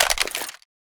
m4a1_clipout.mp3